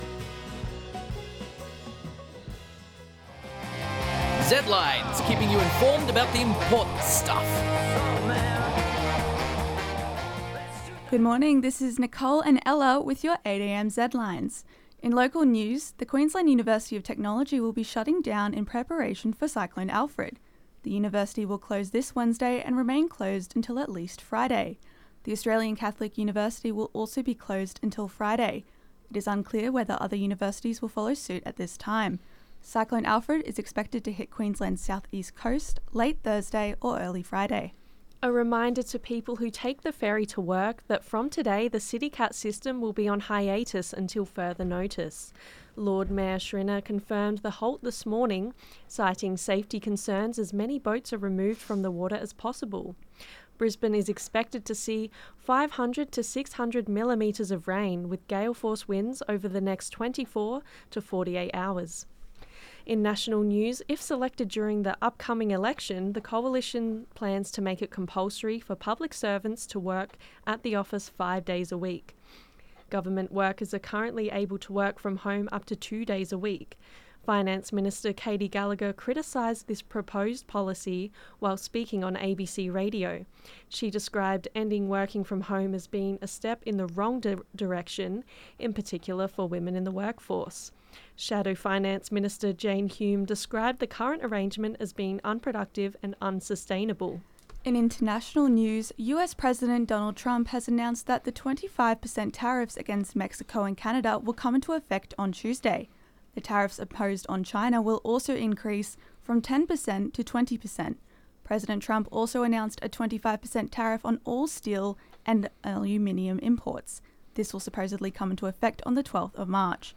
Eye of the Storm image from outer space (Pixabay/pexels under CC BY-ND 2.0) Zedlines Bulletin 8AM ZEDLINES 4.3.25.mp3 (5.77 MB)